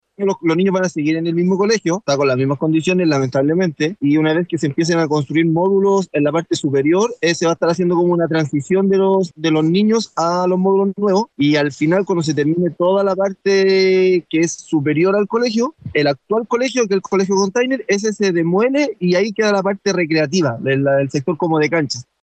Al respecto, el concejal de la comuna de Juan Fernández, Andrés Salas, reveló que una vez que se empiecen a construir los primeros módulos, se iniciará un proceso de transición de los estudiantes.